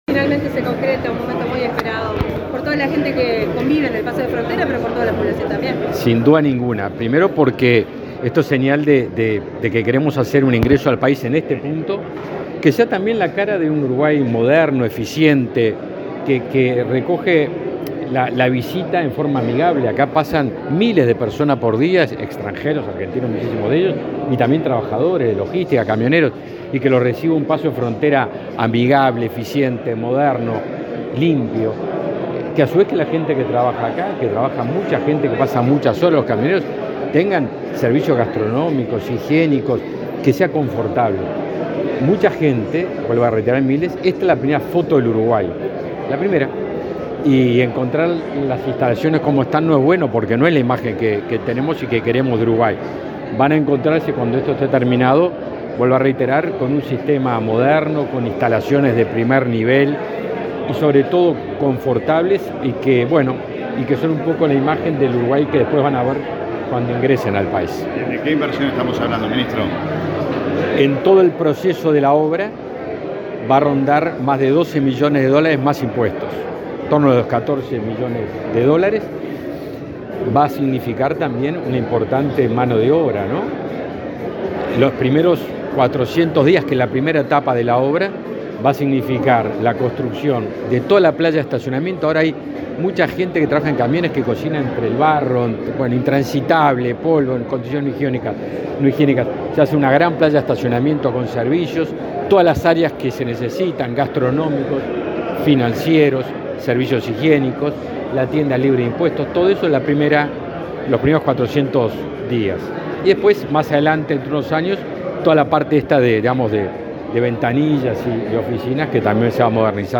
Declaraciones del ministro de Defensa Nacional, Javier García
Declaraciones del ministro de Defensa Nacional, Javier García 30/10/2023 Compartir Facebook X Copiar enlace WhatsApp LinkedIn Este lunes 30 en Fray Bentos, departamento de Río Negro, el ministro de Defensa Nacional, Javier García, firmó un convenio con representantes de la empresa constructora que concretará las nuevas obras del paso de frontera y áreas de control integrado en el puente de esa ciudad. Luego, dialogó con la prensa.